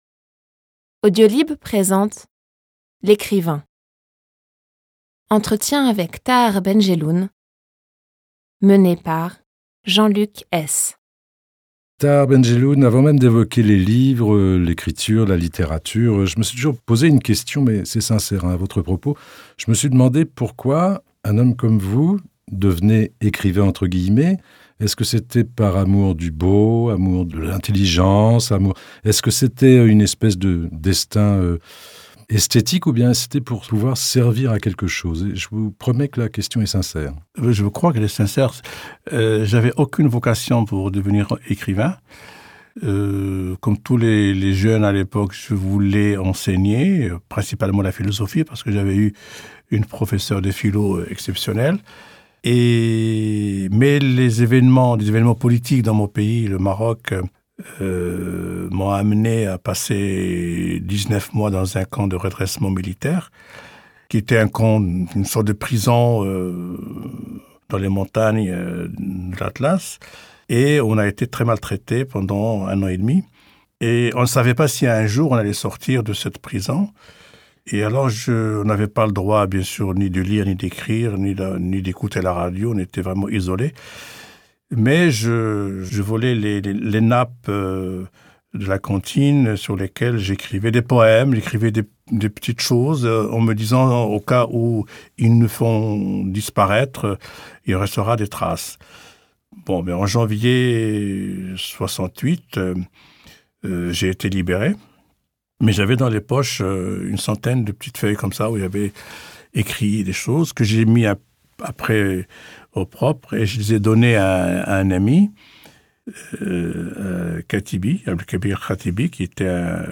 L'Ecrivain - Tahar Ben Jelloun - Entretien inédit par Jean-Luc Hees